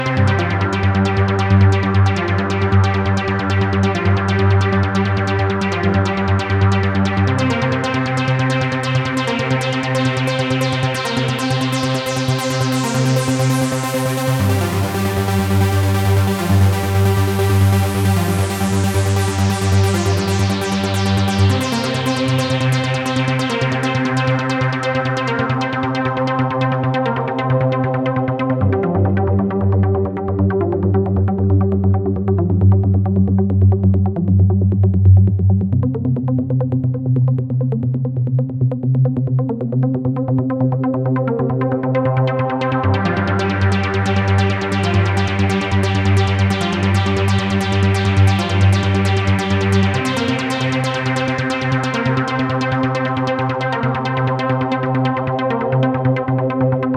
Substanzlos, Resonanzen, klingt mehr nach Rauschen mit etwas Ton, dazu Phasenprobleme Sozusagen der Engl Amp unter den Supersawkopien.